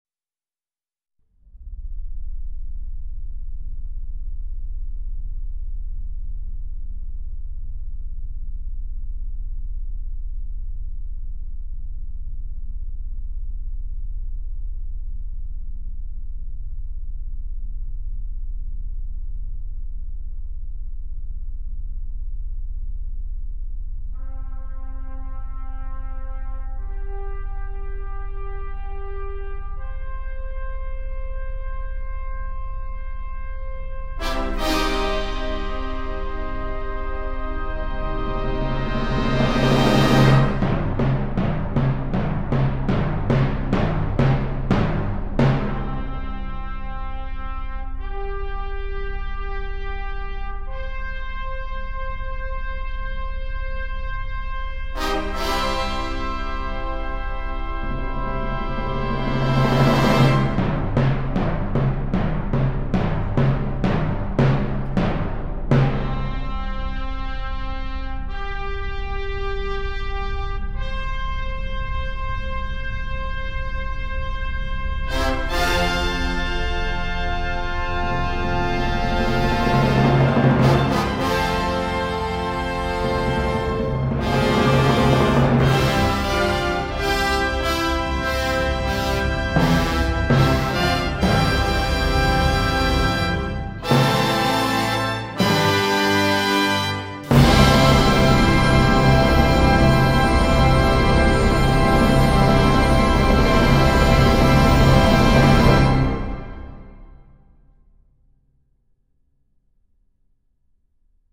But as C3 came on, the sun breaking forth  at the lunar periphery, we were treated to a spectacular 1000 karat version perfectly in keeping with Richard Strauss’ Also Spracht Zarathustra fanfare.